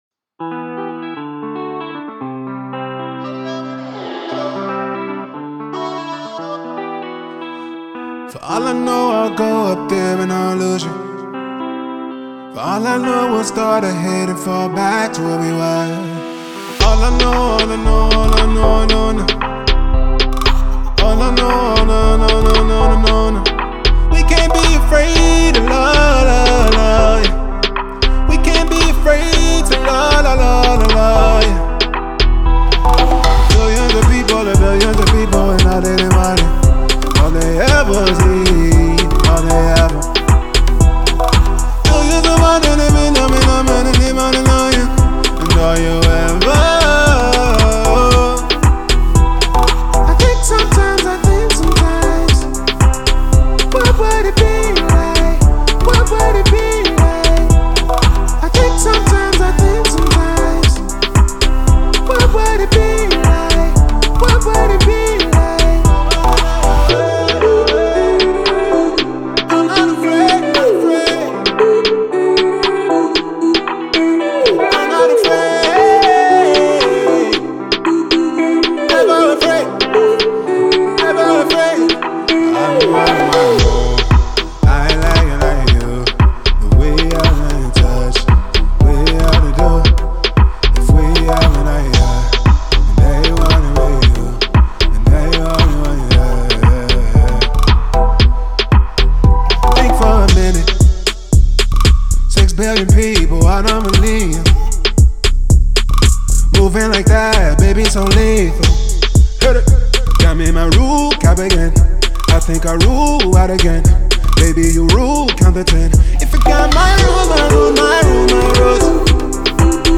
Multi-talented singer and record producer